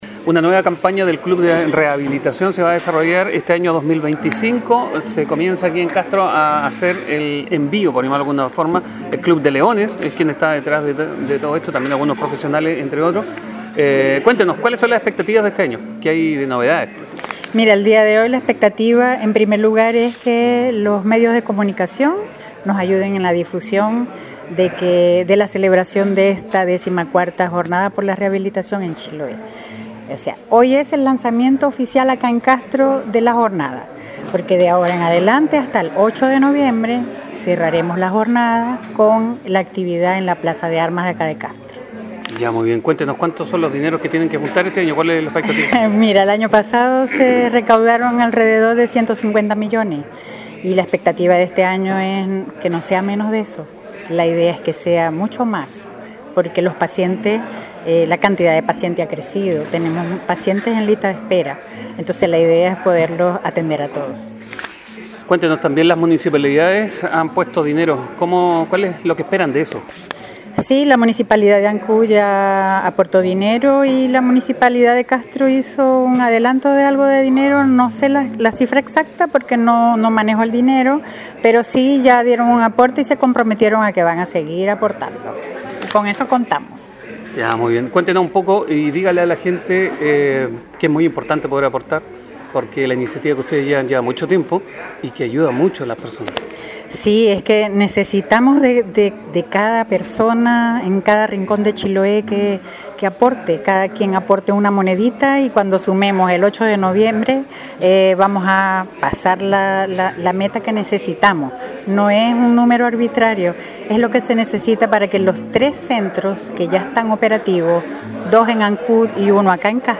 En siguiente informe y todo sus pormenores